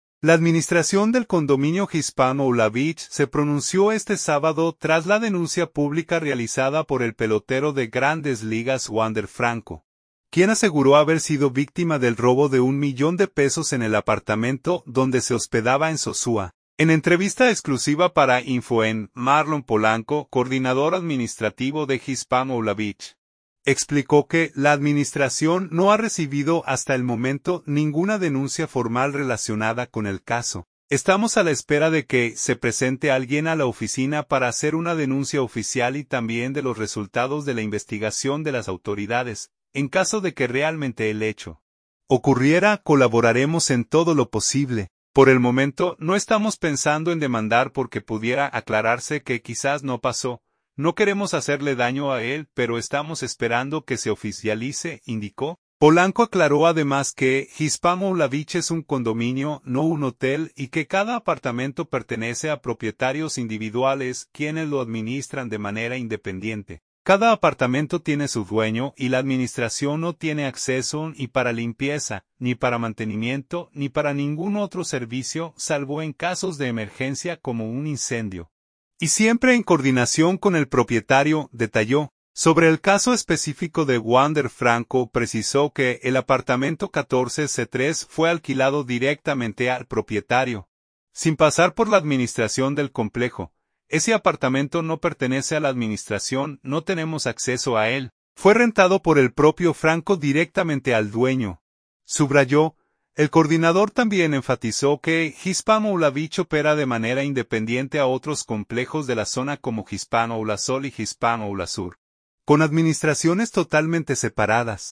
En entrevista exclusiva para InfoENN